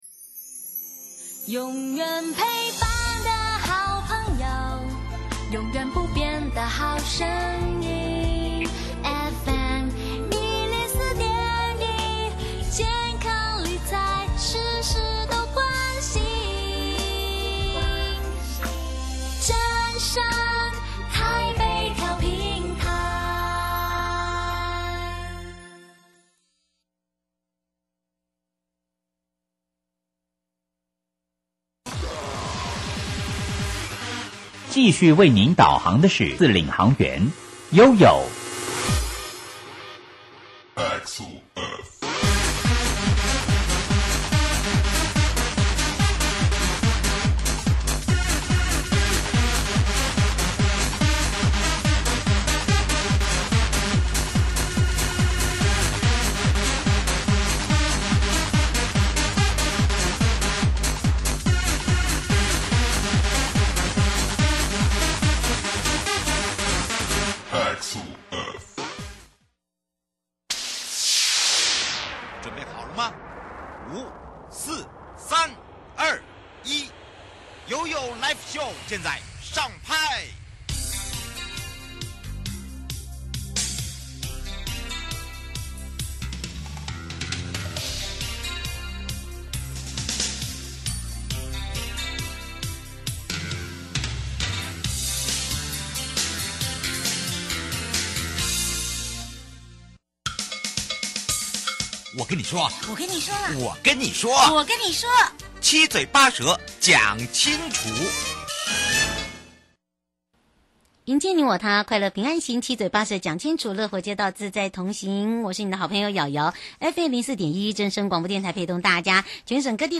受訪者： 營建你我他 快樂平安行-城鄉新風貌計畫 我們政府有在做嗎 ?多久了?我們挑戰很大嗎 ?